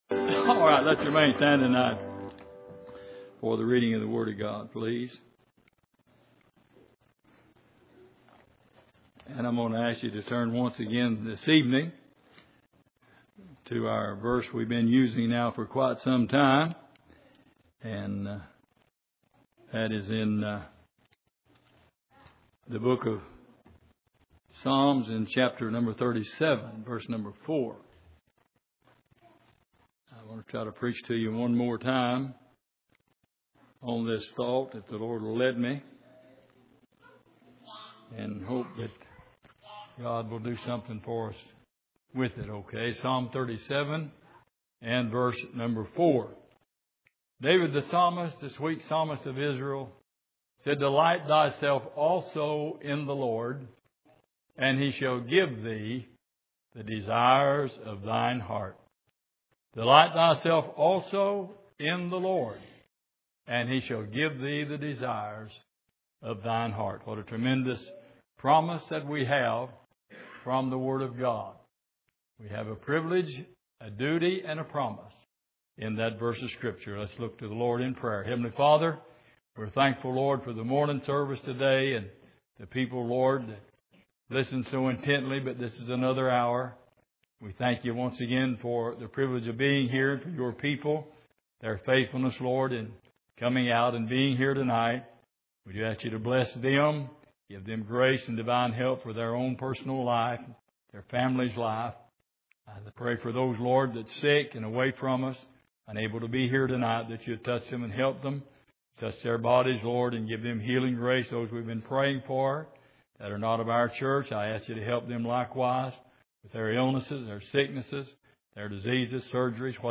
Exposition of the Psalms Passage: Psalm 37:4 Service: Sunday Evening Delight And Desires Part 4 « What Stopped The Water?